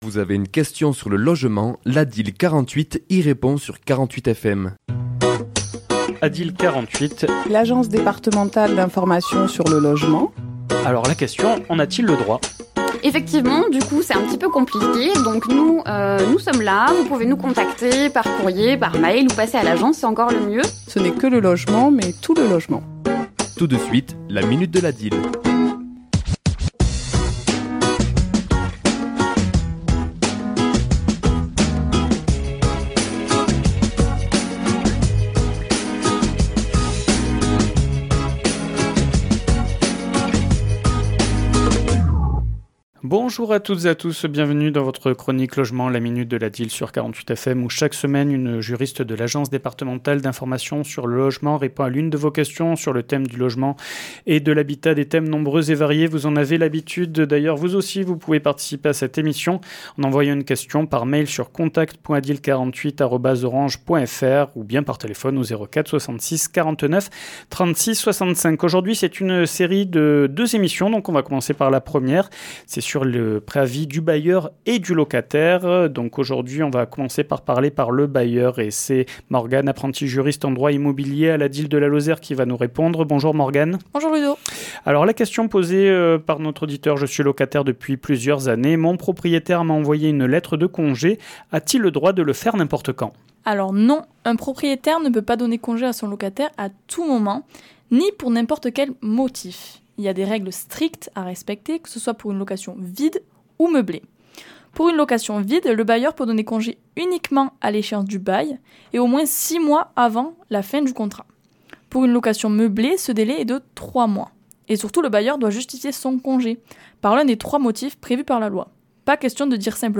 Chronique diffusée le mardi 20 mai à 11h et 17h10